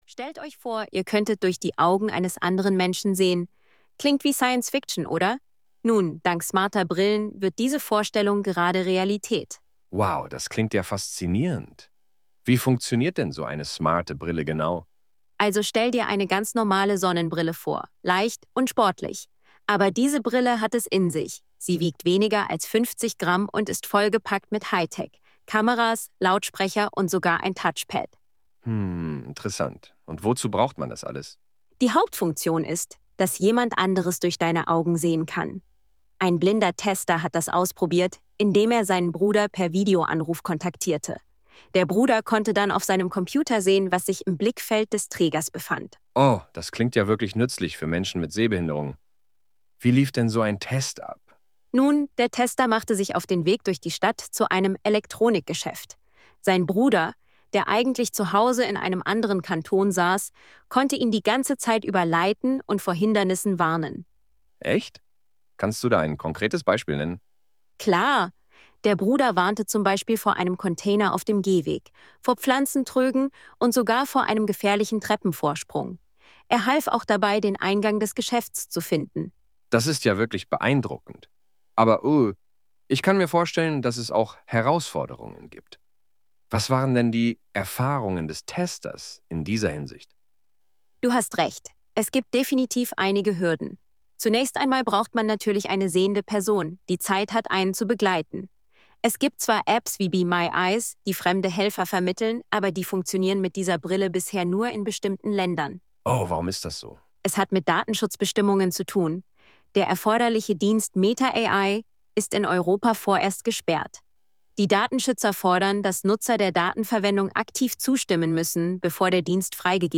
Die App «Elevenreader» kann neu aus einer Textdatei  oder einer Internetseite auf Knopfdruck einem Podcast erstellen, in welchem der Inhalt durch KI-Moderator*innen in einem Dialog vorgestellt wird.
In diesen wird der Inhalt durch zwei KI-Moderatoren (standardmässig eine männliche und eine weibliche Stimme) vorgestellt, d.h. die beiden unterhalten sich über den Text, wobei die eine Stimme die Fragen stellt und die andere antwortet.